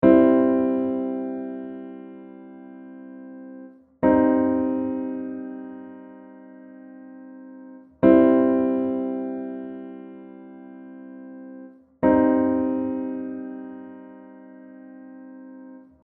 2. Riconoscere accordi maggiori e minori
Accordo maggiore → il III grado è naturale (Do-Mi-Sol)
Accordo minore → il III grado è abbassato di un semitono (Do-Mib-Sol)
Associare la sonorità a un’emozione aiuta: maggiore = felicità, minore = tristezza.
maggiore-minore.mp3